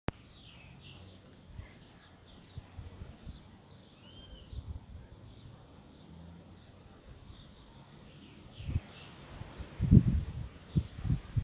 Golden-billed Saltator (Saltator aurantiirostris)
Detailed location: Bosque de caldenes detrás Hotel La Campiña
Condition: Wild
Certainty: Recorded vocal
Pepitero-de-Collar-audio.mp3